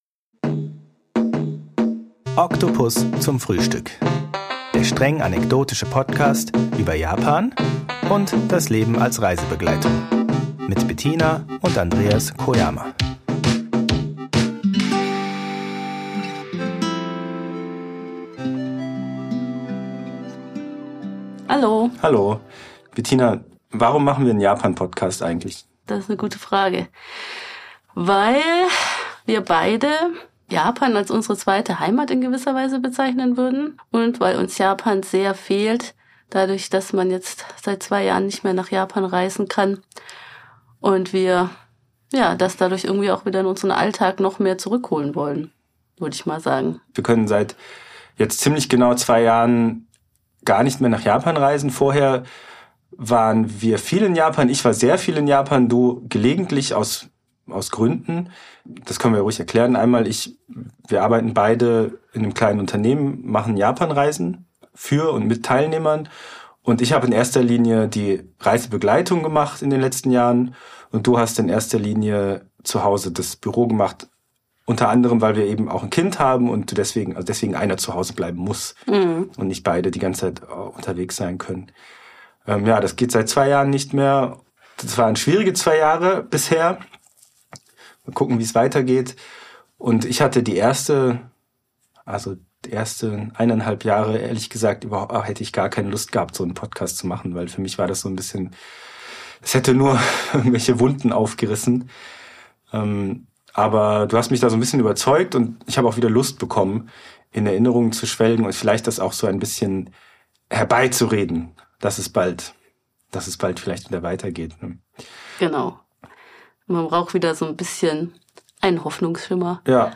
Von Tropfen auf dem Studiodach inspiriert, wandern ihre Gedanken zu Regentagen in Japan. Dabei ist nicht immer alles fröhlich aber definitv feucht.